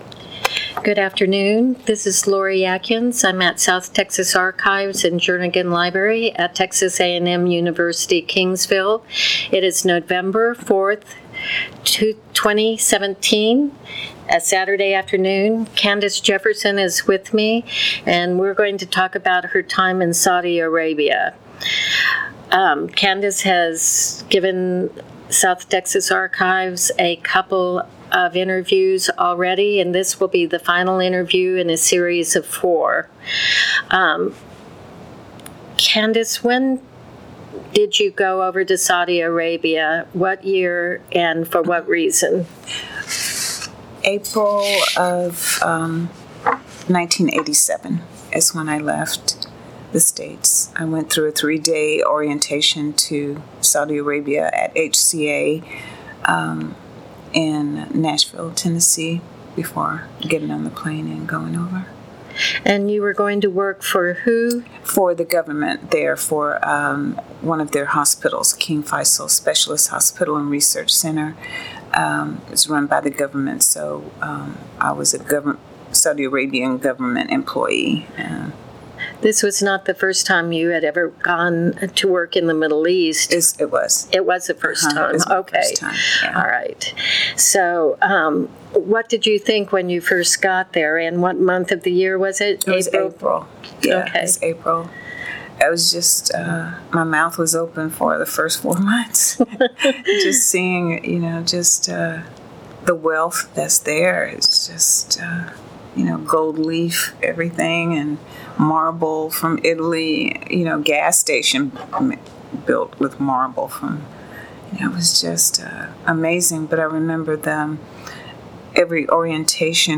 Oral History Masters